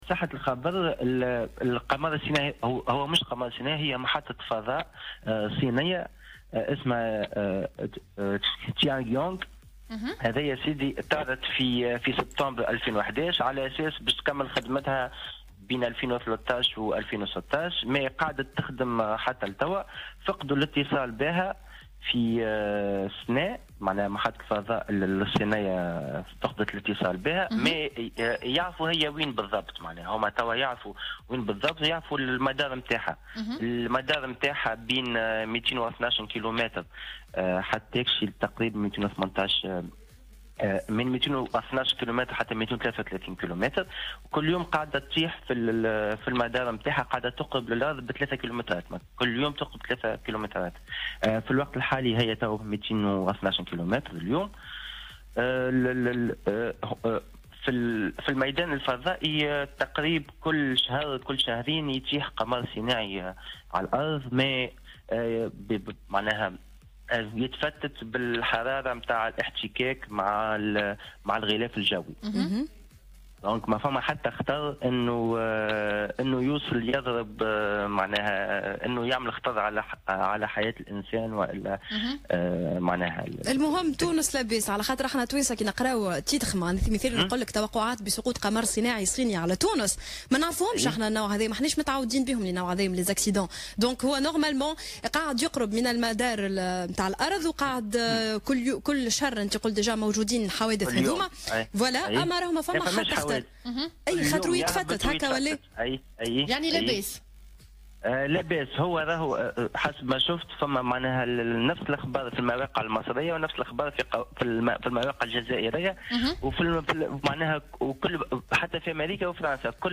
خلال مداخلته في برنامج "Happy-Days"